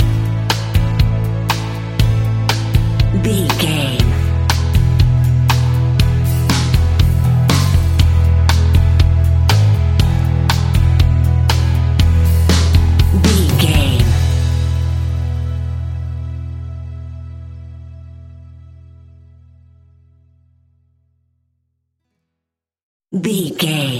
Ionian/Major
pop rock
energetic
uplifting
cheesy
guitars
bass
drums
organ